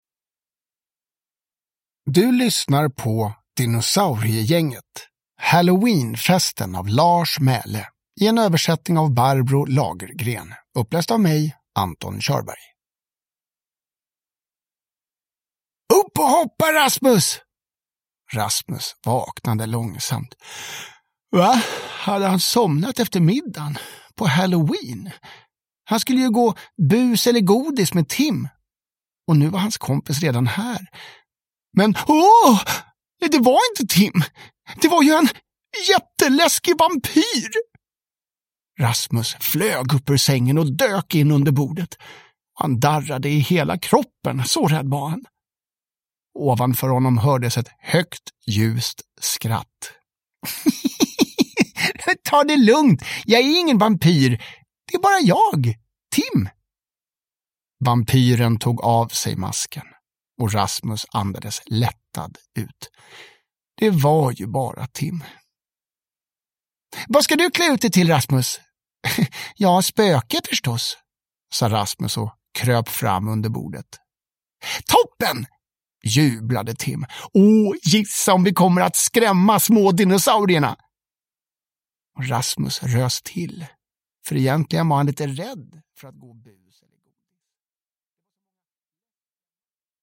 Halloweenfesten (ljudbok) av Lars Mæhle